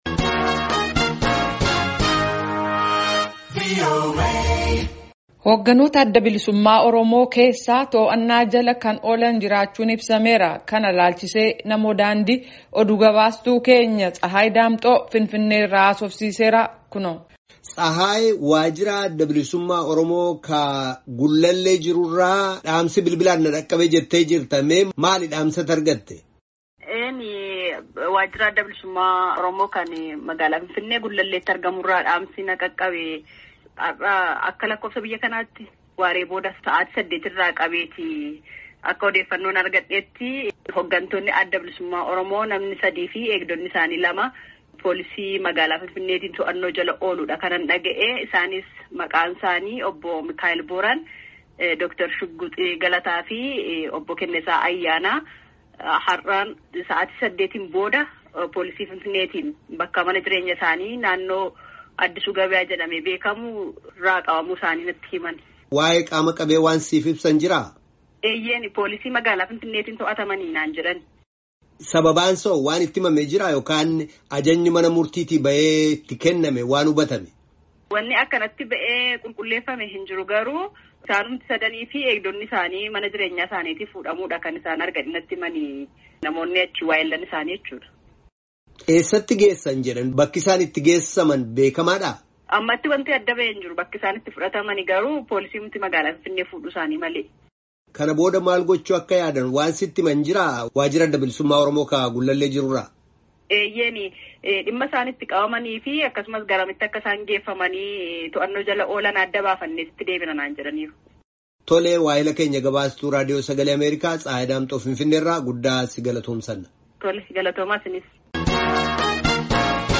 Gaaffii fi deebii gabaastuu VOA